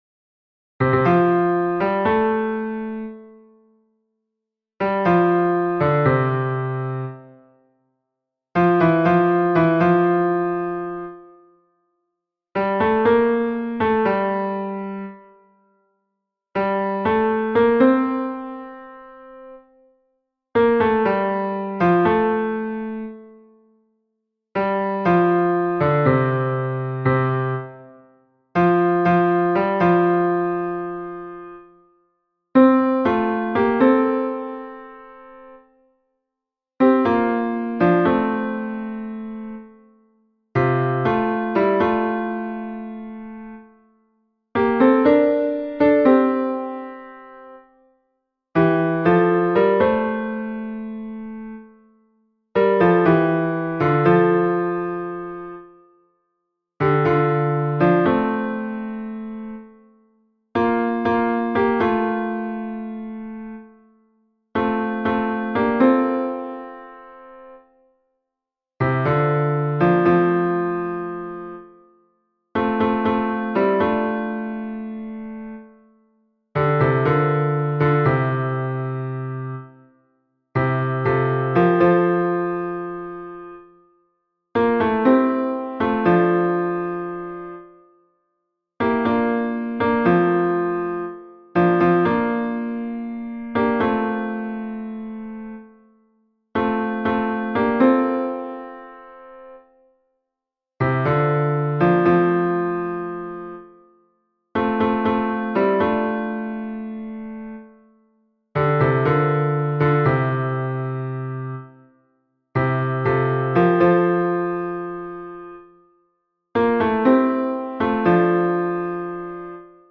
MP3 version piano
Hommes Piano